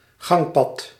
Ääntäminen
US : IPA : [aɪl] UK : IPA : /aɪ̯l/